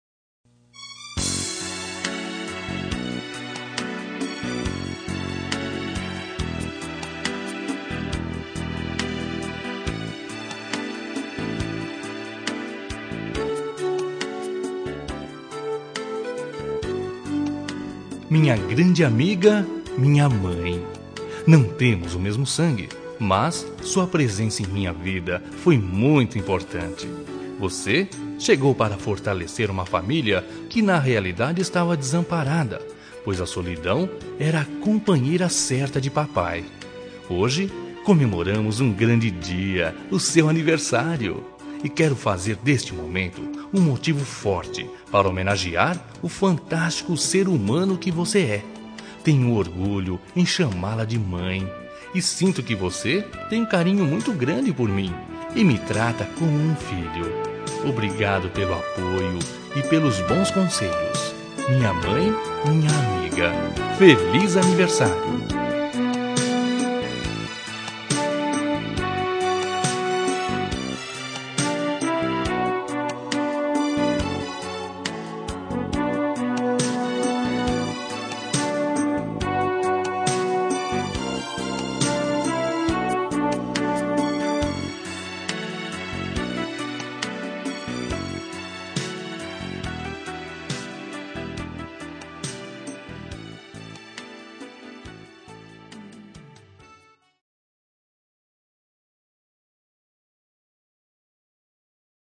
Telemensagem de Aniversário de Mãe – Voz Masculina – Cód: 1441 – Madrasta